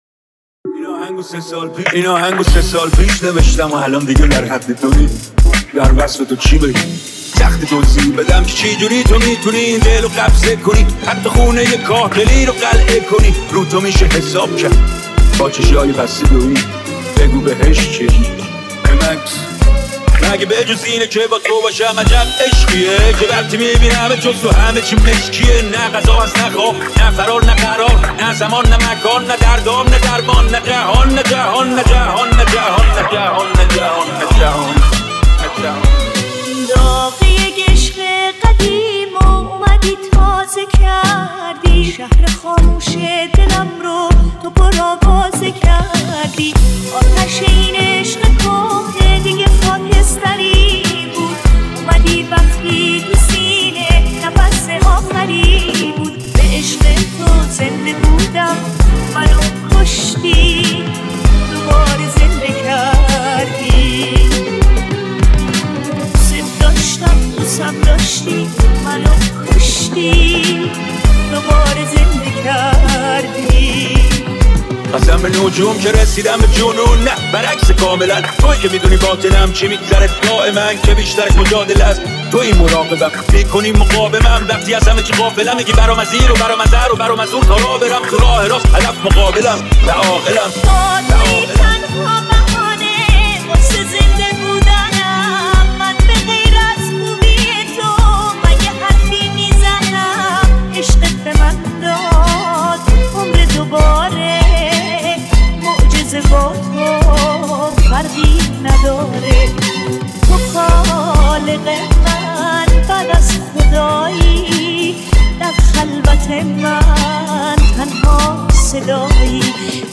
ریمیکس با رپ